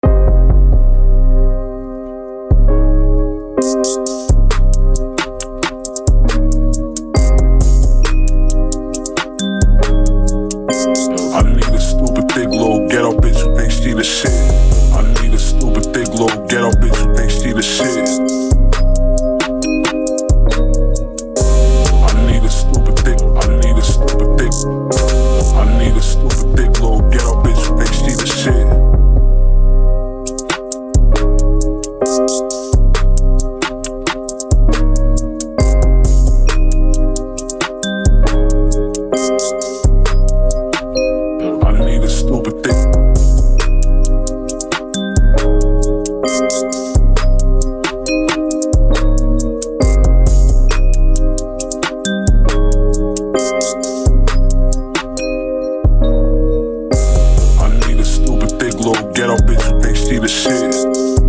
Miami Club Type Beats
Hip Hop Industry Type Rap Beat